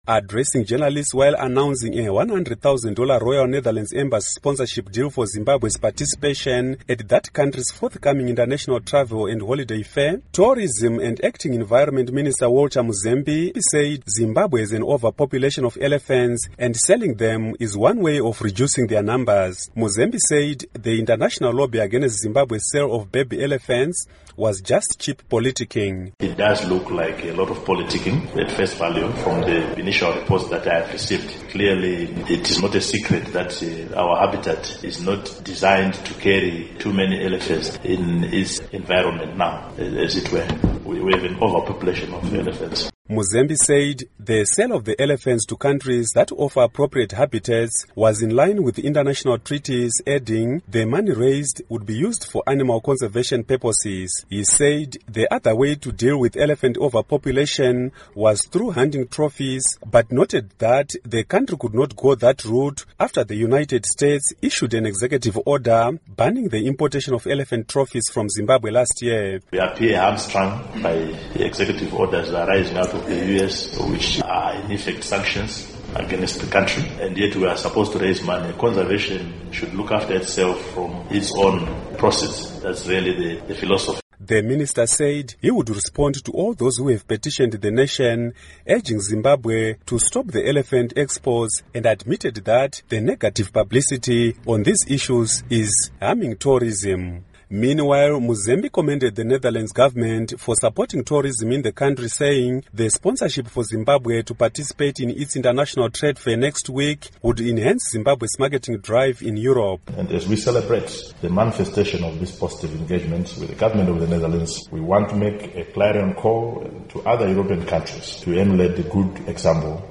Tourism and Acting Environment Minister, Walter Muzembi, told journalists in Harare that Zimbabwe has an overpopulation of elephants and selling them is one of reducing their numbers.